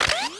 MOTRSrvo_Plasma Rifle Arm_01.wav